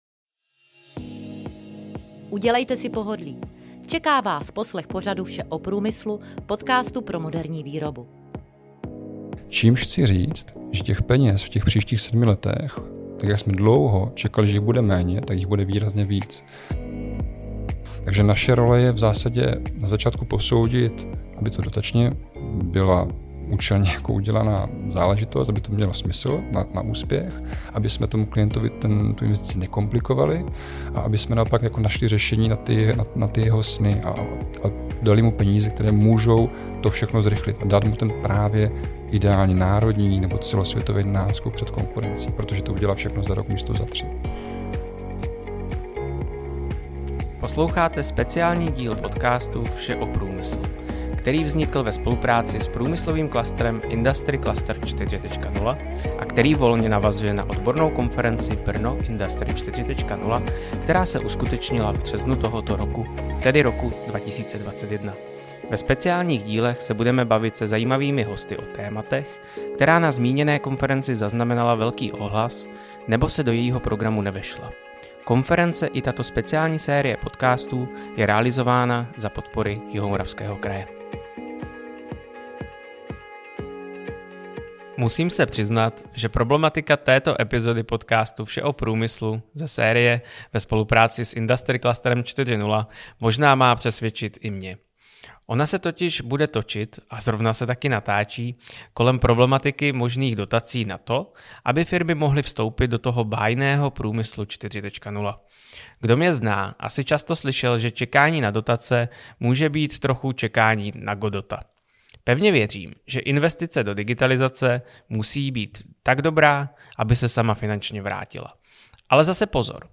Co jej k té změně přimělo je obsahem tohoto rozhovoru.